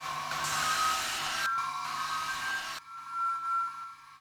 Dans les arbres material manipulated and treated electronically – in multi-track editing software and later, in the ‘Electronic Mirror’.
(00:04) Air sounds cut and pasted together in a collage. Made in a multi-track editing software.
IG_event_air1.mp3